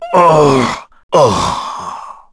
Crow-Vox_Dead_kr.wav